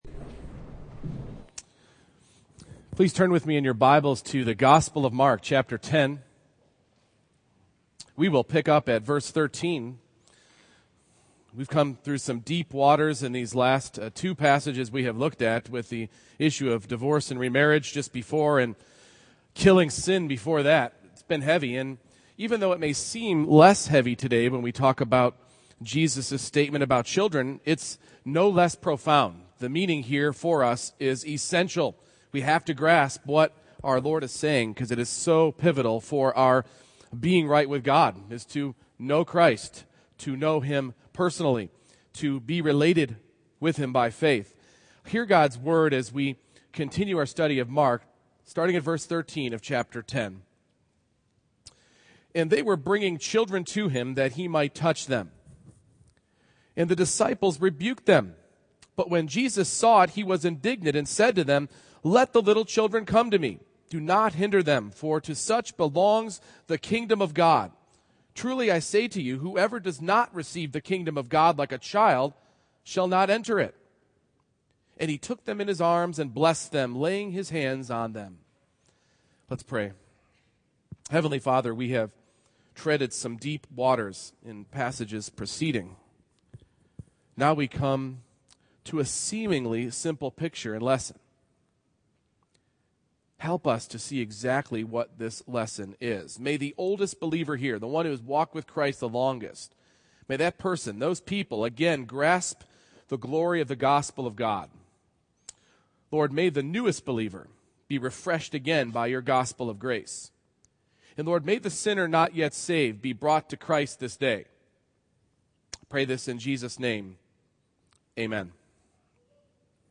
Mark 10:13-16 Service Type: Morning Worship Entrance into the Kingdom of God is not something that can be earned